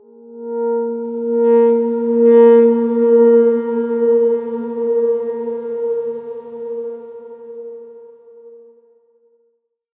X_Darkswarm-A#3-f.wav